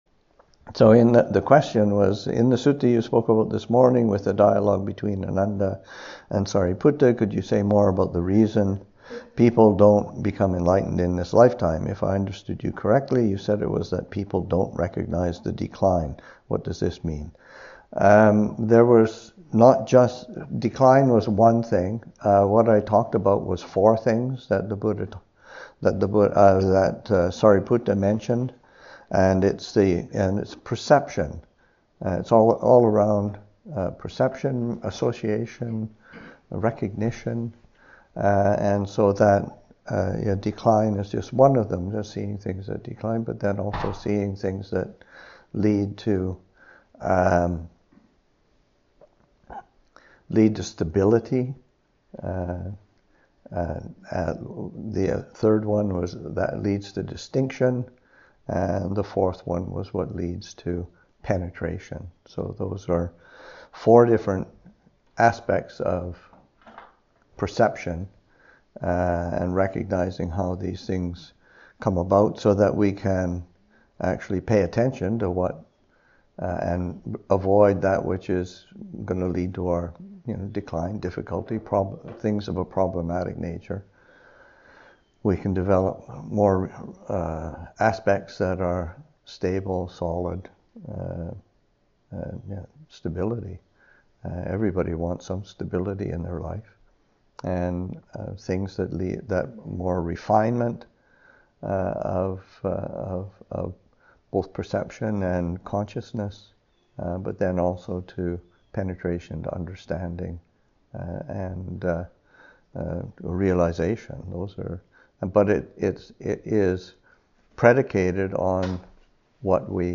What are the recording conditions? A weekend residential retreat organized by Madison Insight held at Pine Lake Retreat Center in rural Wisconsin.